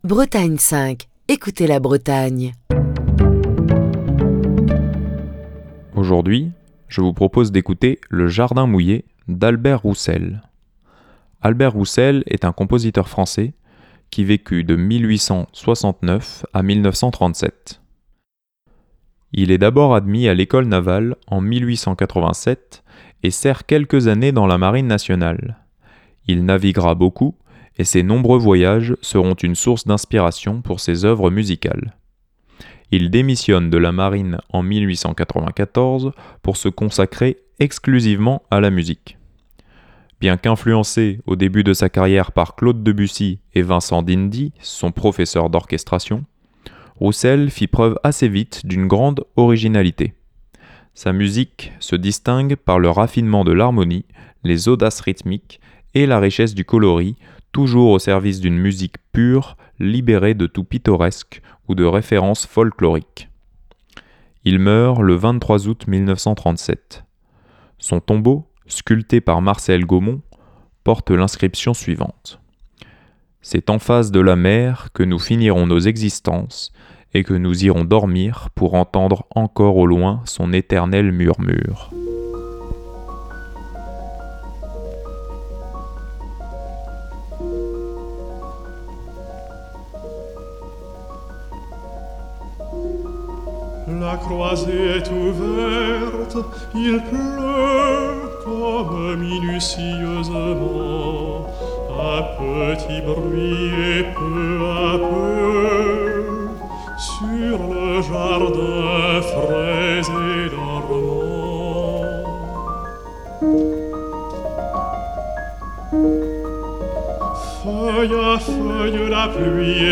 Sa musique se distingue par le raffinement de l'harmonie, les audaces rythmiques et la richesse du coloris toujours au service d'une musique pure libérée de tout pittoresque ou de références folkloriques.